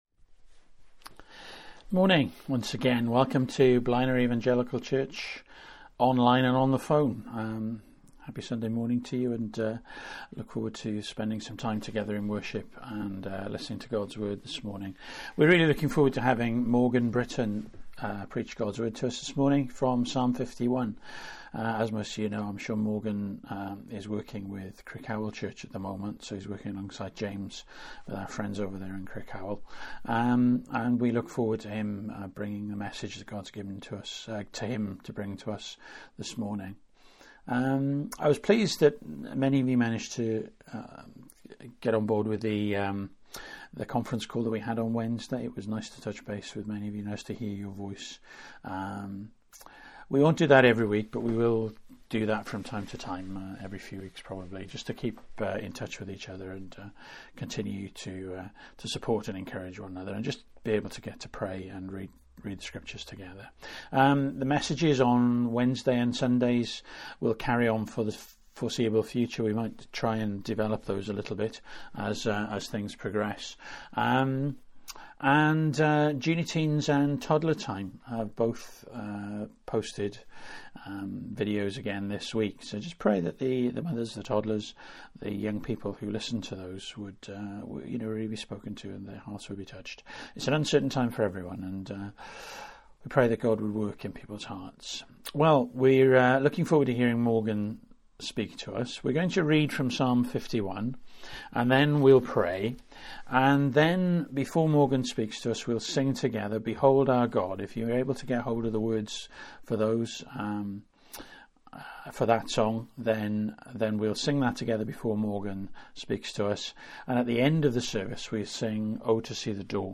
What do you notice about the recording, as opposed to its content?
Psalm 51 Service Type: Morning Bible Text